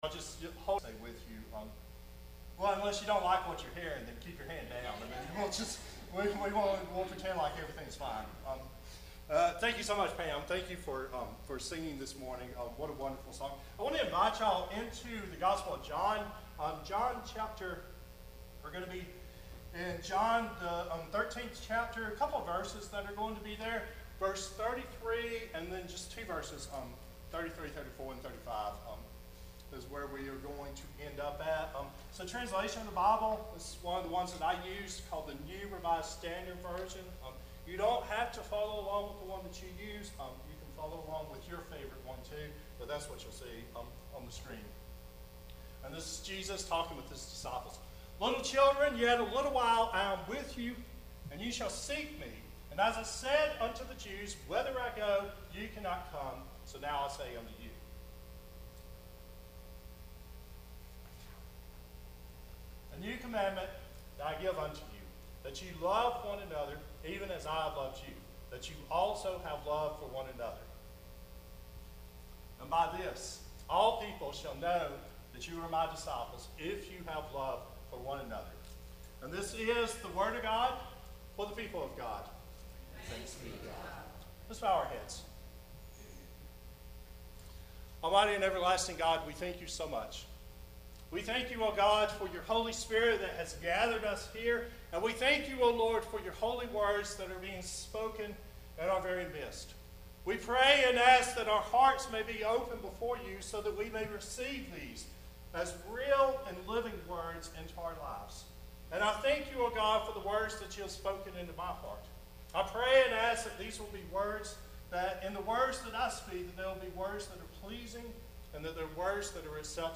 Sermons - GracePoint at Mt. Olive